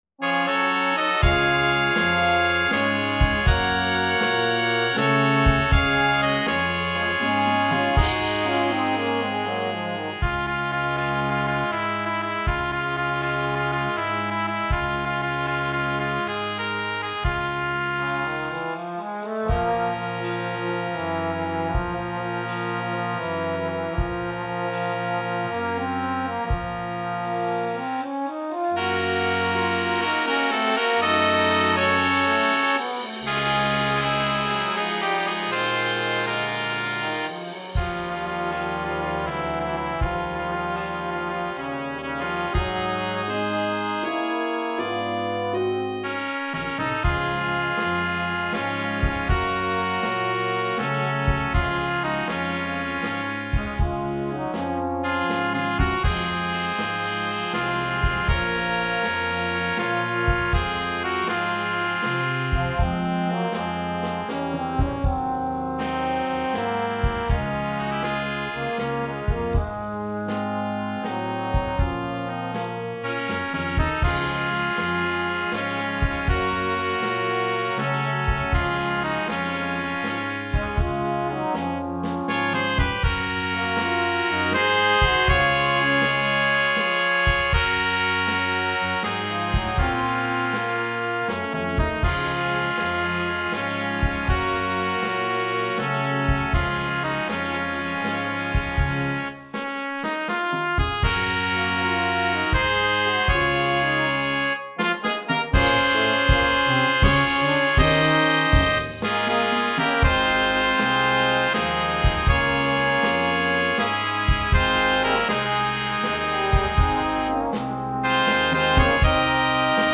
Gattung: Schlager für kleine Besetzung
Besetzung: Kleine Blasmusik-Besetzung
Stimmensatz Sextett:
1. Stimme in Bb/C (Trompete 1 / Flügelhorn 1)
4. Stimme in Bb/C (Posaune 1 / Tenorhorn)
6. Stimme in C/Eb (hoch) (Tuba 1)